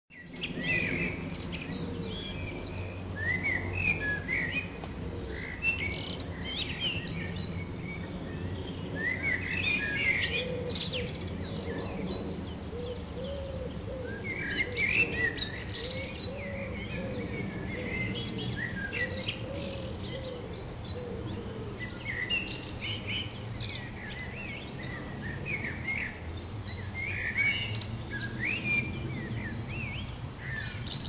Lindfield, sounds of spring